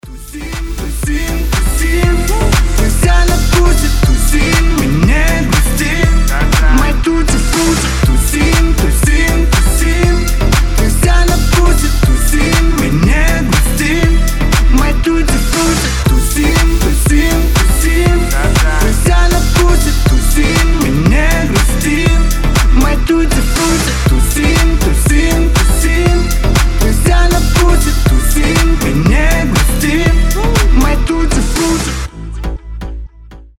• Качество: 320, Stereo
позитивные